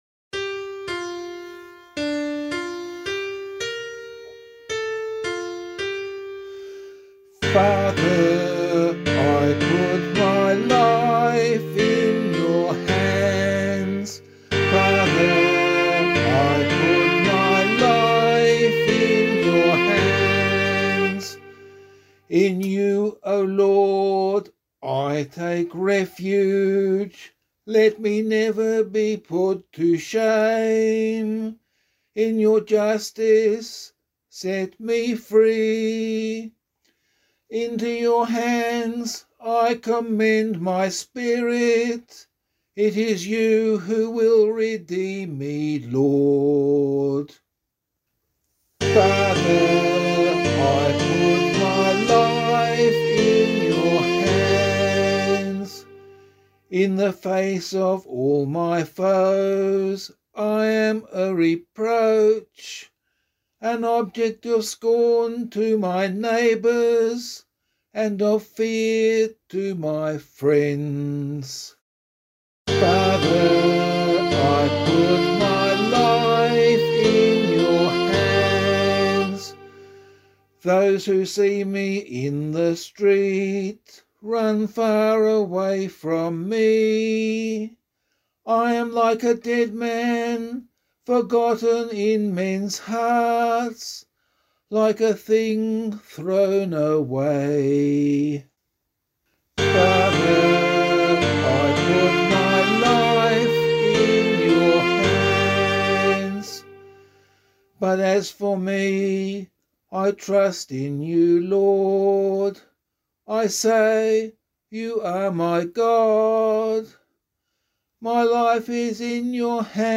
021 Good Friday Psalm [LiturgyShare 3 - Oz] - vocal.mp3